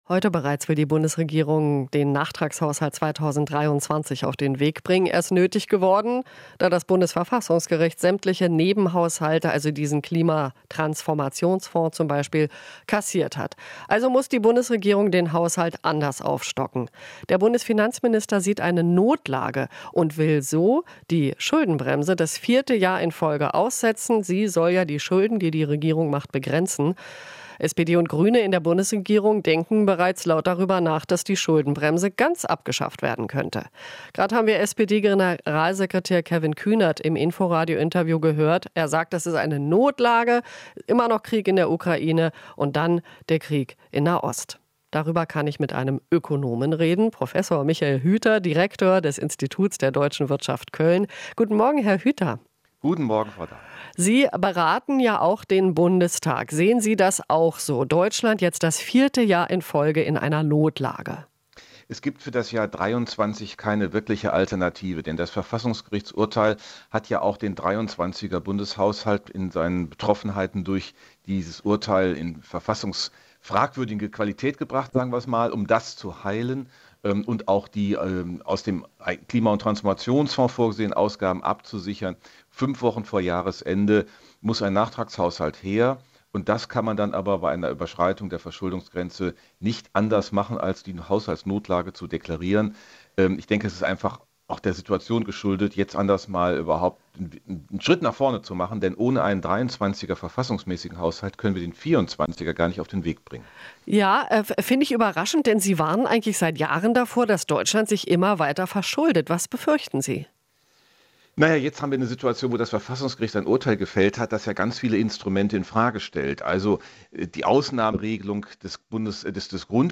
Interview - IW-Direktor Hüther: Aktuell keine Alternative zur "Notlage"